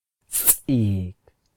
The alveolar ejective fricative is a type of consonantal sound, used in some spoken languages.
Tlingit[4] eek
[sʼiːkʰ] 'bear'